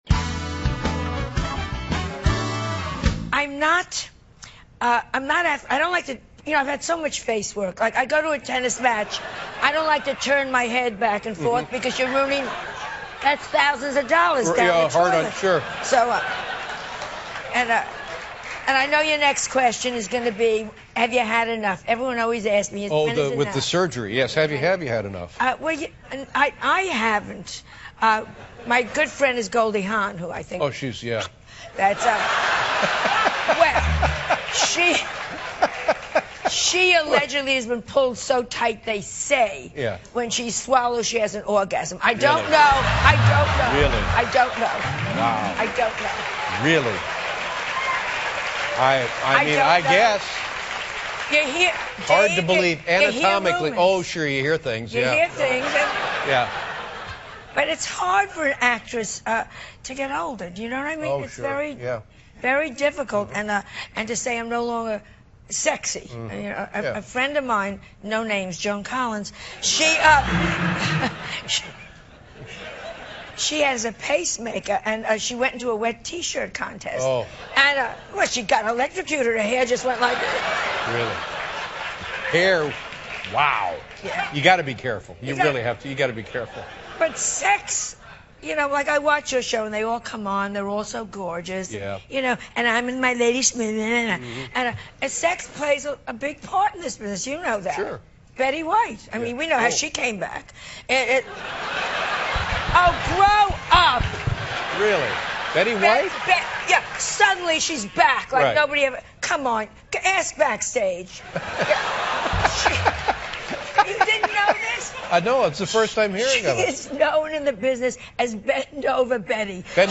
访谈录 2012-02-21&02-23 最幽默的女人琼·里弗斯谈整容 听力文件下载—在线英语听力室